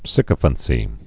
(sĭkə-fən-sē, sīkə-)